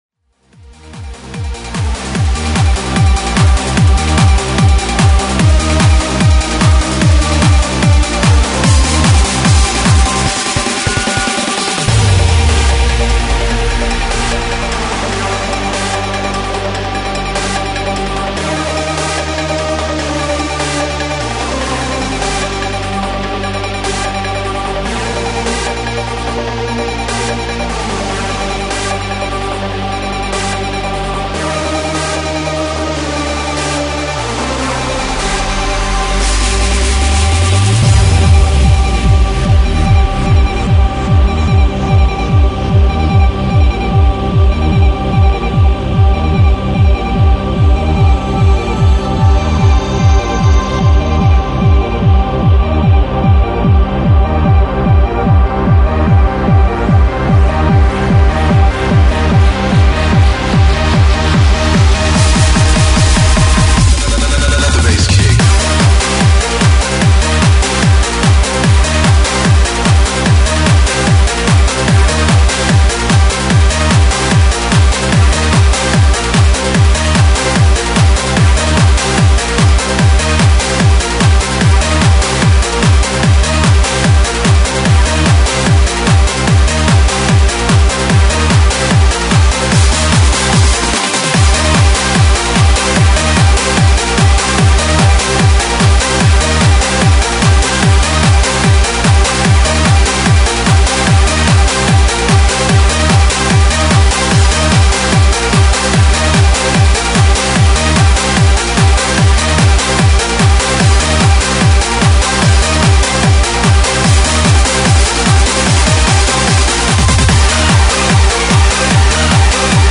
Hard House/Hard Trance/Freeform